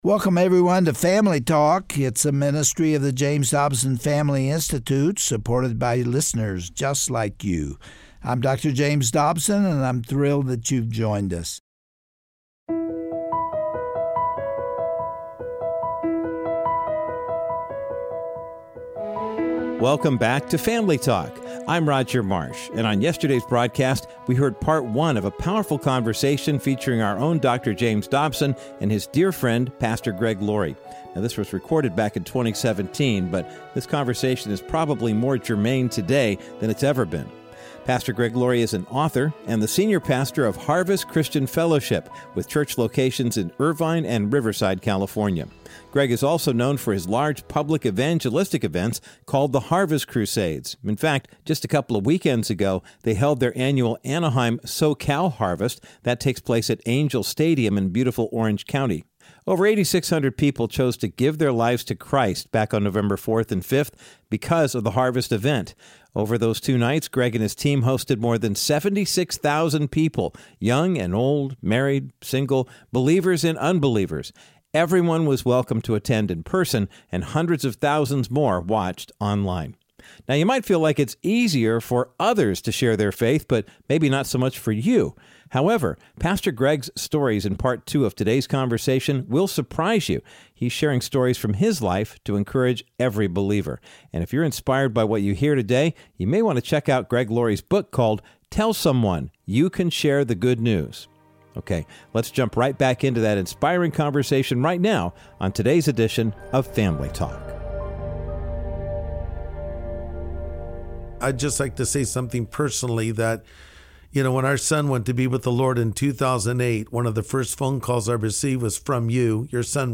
On today’s edition of Family Talk, Dr. James Dobson and Pastor Greg Laurie continue to share biblical principles to encourage believers to share their faith and bring others into the family of God.
Host Dr. James Dobson